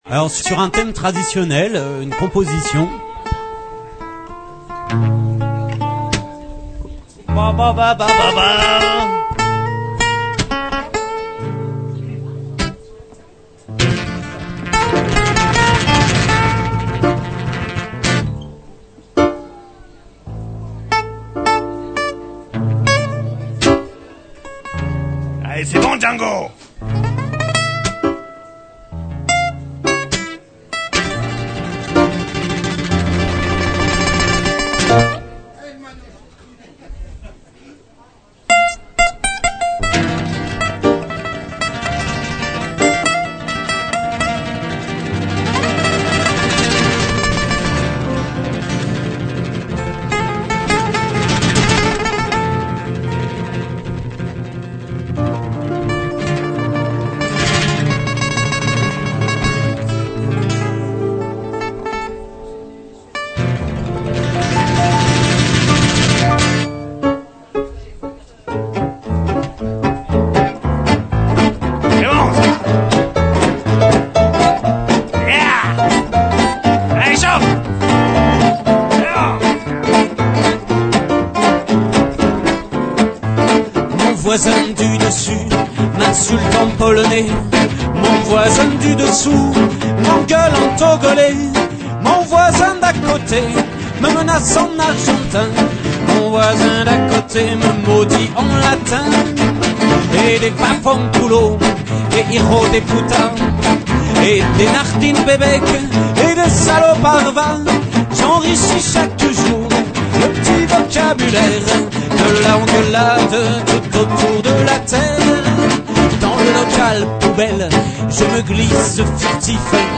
Dm Moderato
live